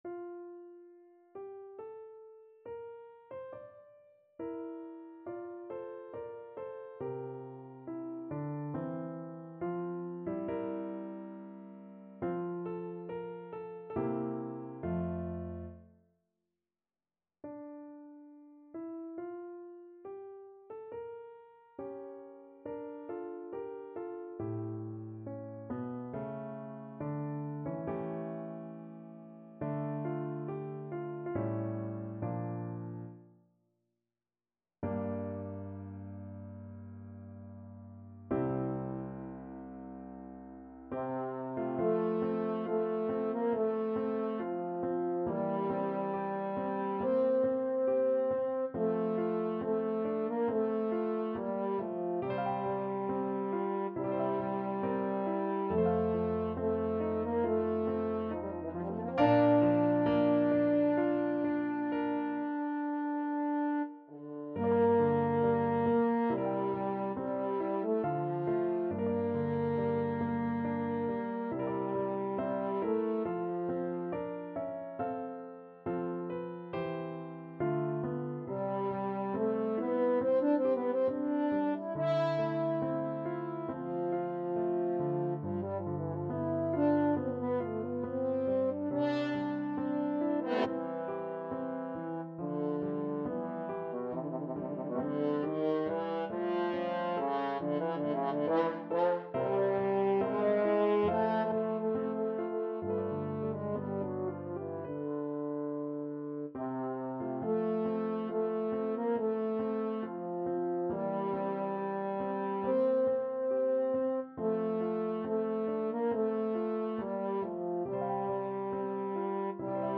Classical Chopin, Frédéric Piano Concerto No.1 (Op. 11) Second Movement Main Theme French Horn version
French Horn
F major (Sounding Pitch) C major (French Horn in F) (View more F major Music for French Horn )
4/4 (View more 4/4 Music)
Larghetto (=80) =69